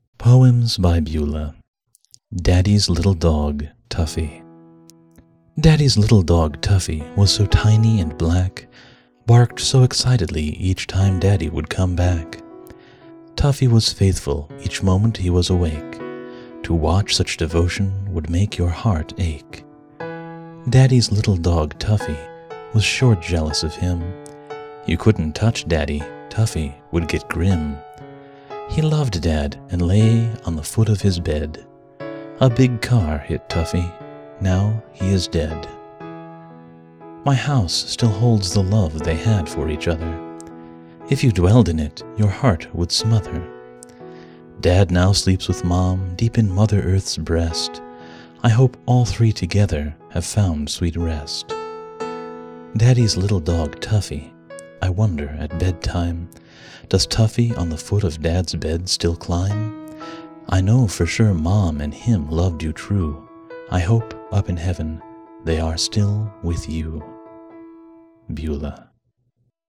Sadly, it’s not a terribly good listen. My voice doesn’t seem to do well with either mp3 or m4a compression, so I sound like I’m speaking through a can of gravel.